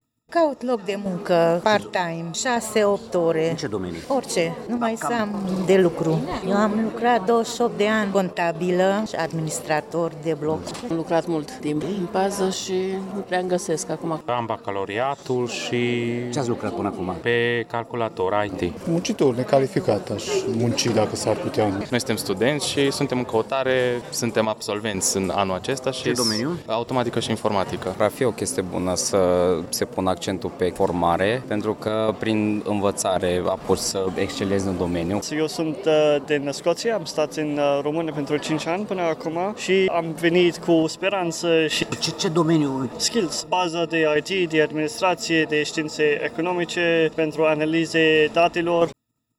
La târg au venit să își caute un job studenți și persoane de peste 45 de ani, muncitori necalificați sau specialiști cu studii superioare. Toți sunt conștienți de criza economică a momentului: